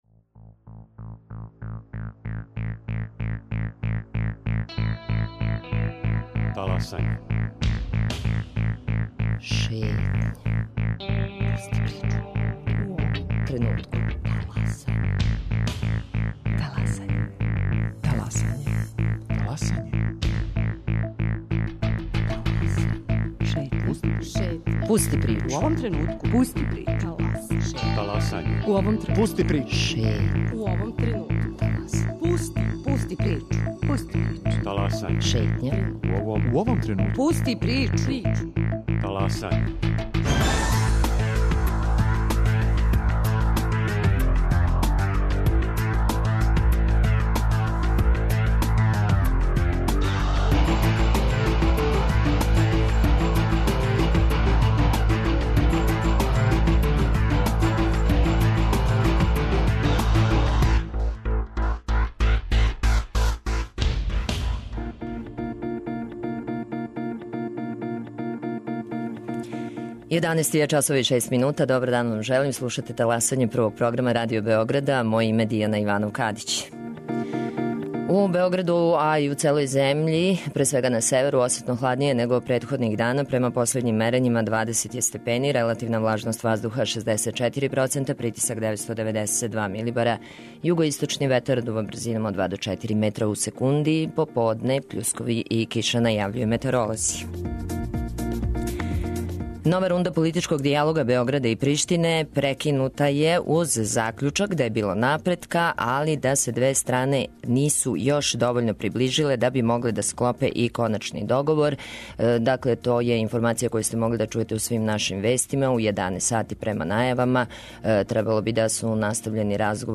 Гости: Дејан Павићевић, шеф тима за техничке преговоре, Слободан Самарџић потпредседник ДСС-а и Зоран Остојић, посланик ЛДП-а у Скупштини Србије.